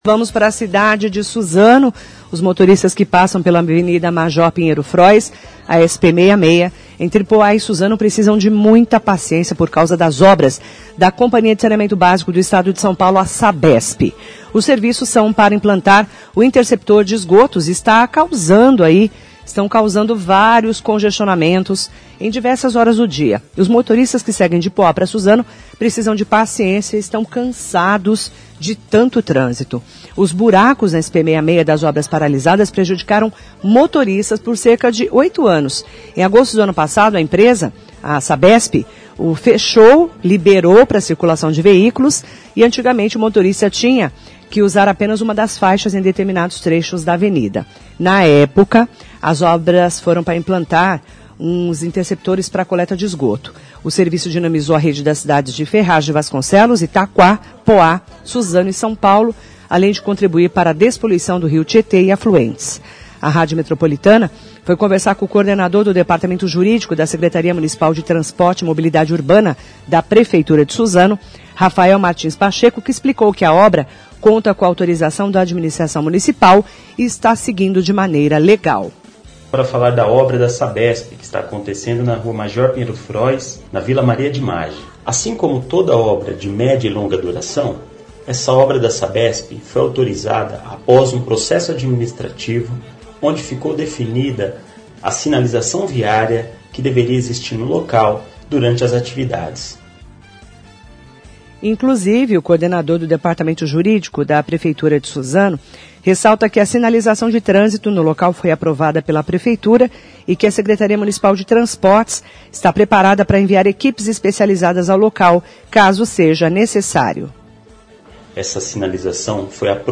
Ouça agora a matéria especial da Rádio Metropolitana.